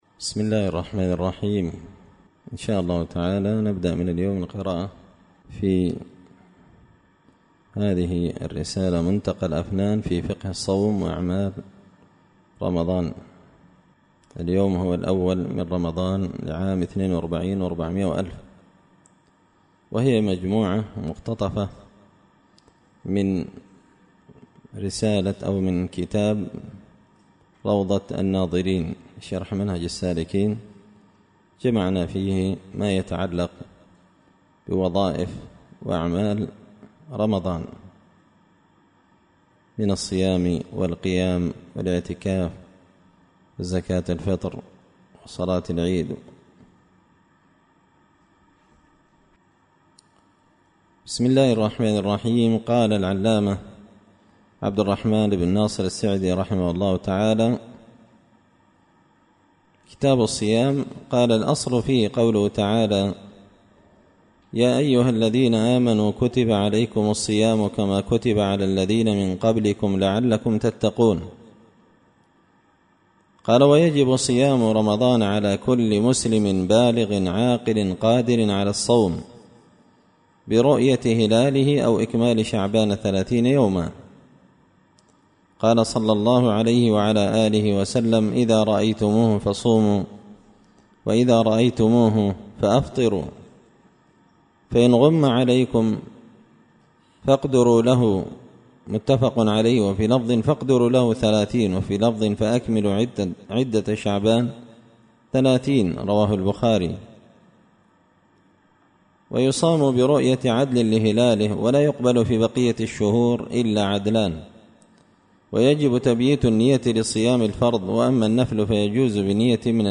منتقى الأفنان في فقه الصوم وأعمال رمضان الدرس الأول تحميل